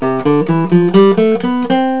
The simplest major scale consists of the natural notes starting from C. The order of the notes is C, D, E, F, G, A, B, C. As long as there are 7 unique notes (8 counting the first and last notes that are the same), with a half step between notes 3 to 4, and notes 7 to 8 and whole steps between the other notes, the scale is major.
ScaleMaj.au